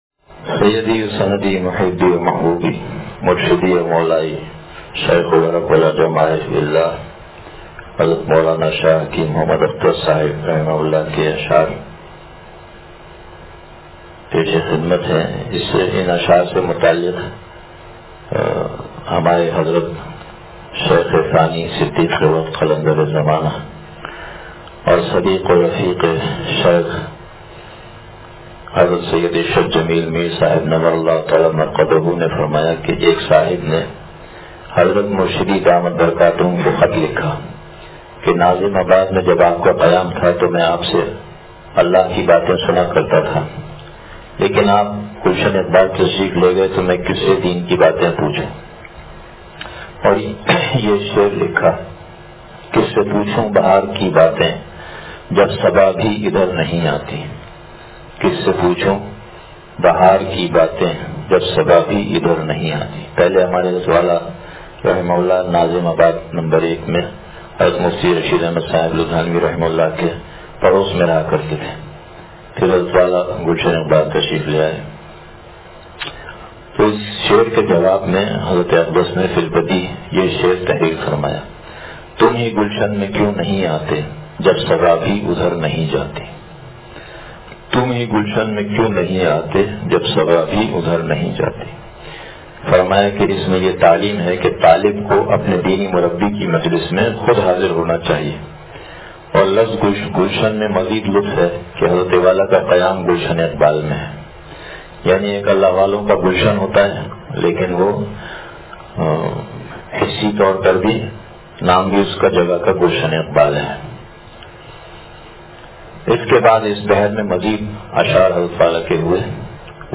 کس سے پوچھوں بہار کی باتیں – اتوار بیان